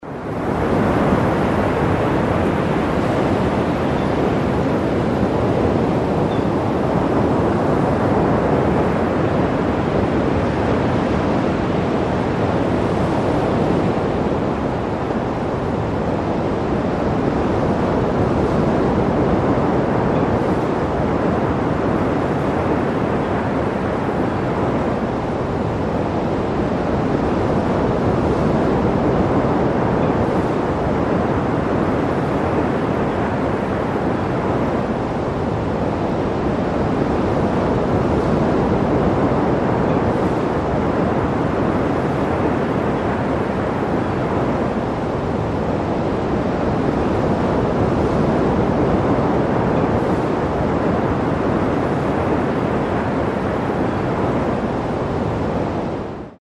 Звуки прибоя
Здесь вы найдете успокаивающие шум волн, грохот прибоя и шелест прибрежного песка.